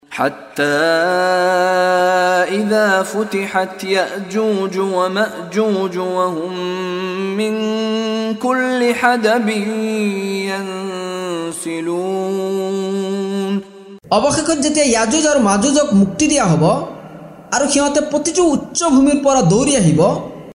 অসমীয়া ভাষাত (ছুৰা আল-আম্বিয়া)ৰ অৰ্থানুবাদৰ অডিঅ ৰেকৰ্ডিং।
লগতে ক্বাৰী মিশ্বাৰী ৰাশ্বিদ আল-আফাছীৰ কণ্ঠত তিলাৱত।